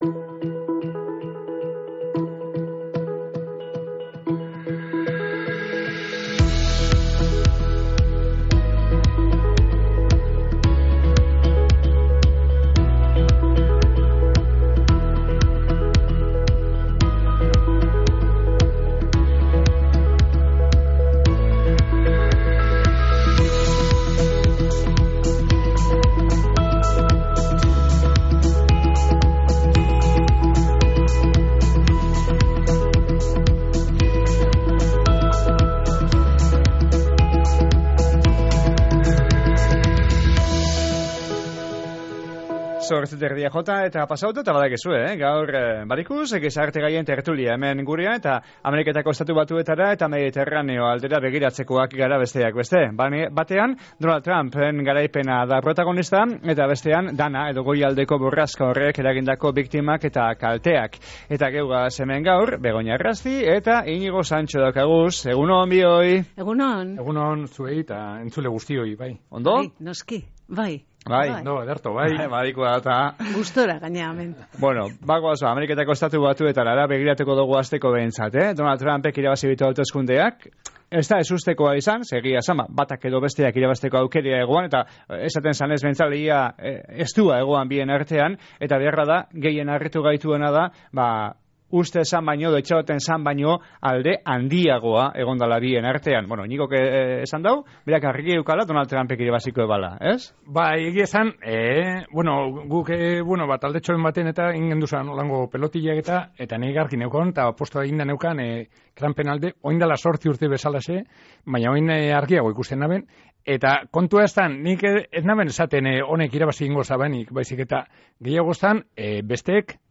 Errepublikarrek hauteskundeak irabazi izana eta Valentzia eta inguruetako egoerea aztertu ditugu tertulian